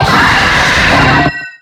Cri de Palkia dans Pokémon X et Y.